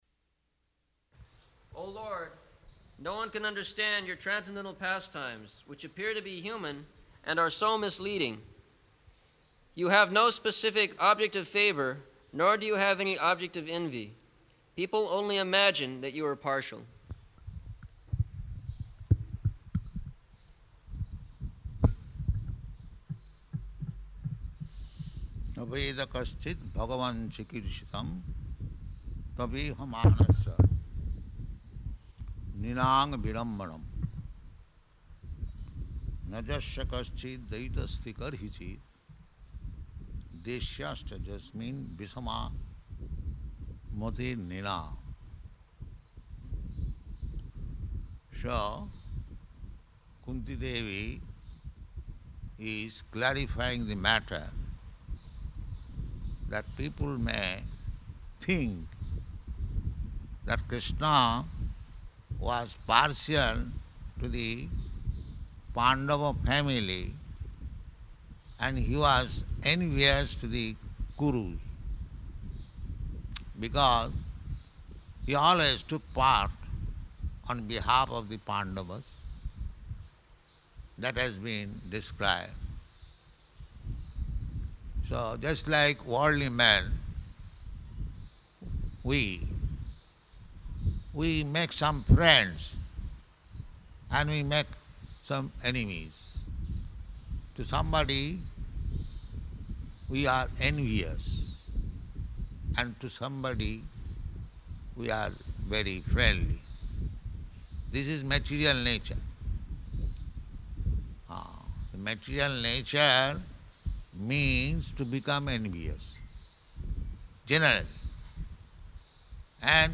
Type: Srimad-Bhagavatam
Location: Māyāpur